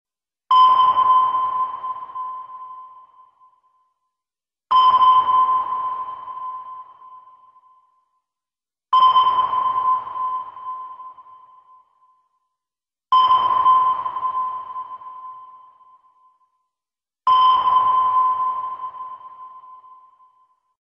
جلوه های صوتی
دانلود صدای رادار کشتی از ساعد نیوز با لینک مستقیم و کیفیت بالا